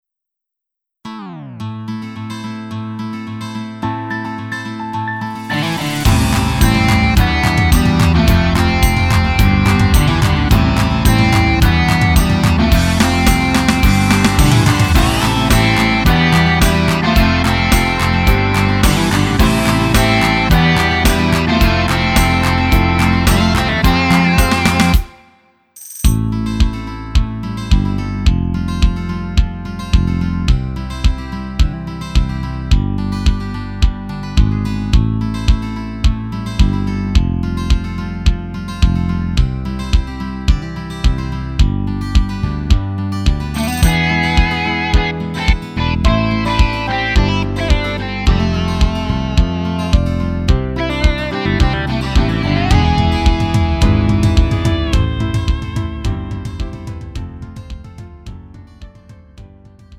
음정 원키 3:00
장르 가요 구분